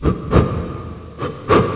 souffle.mp3